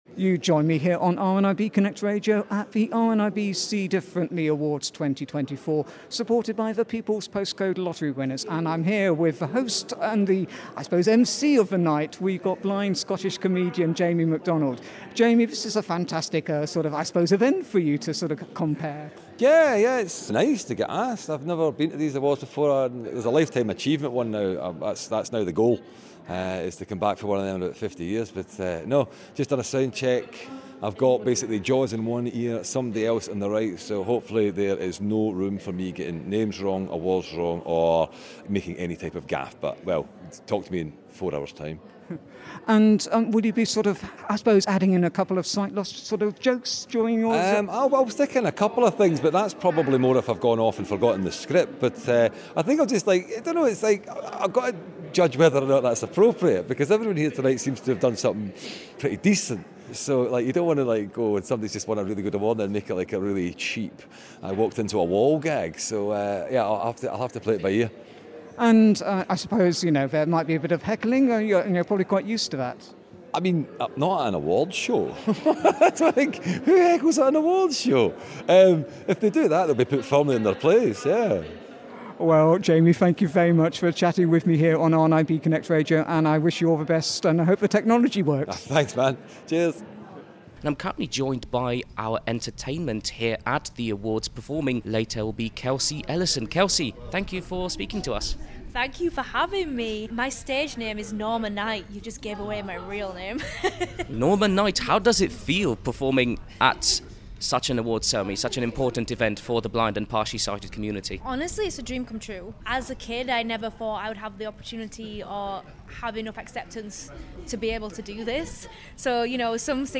The RNIB See Differently Awards 2024 supported by the People’s Postcode Lottery winners took place in London on Tuesday 21st of May.